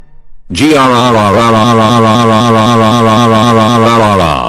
GRRR Sound Effect (Roblox Noobs OS)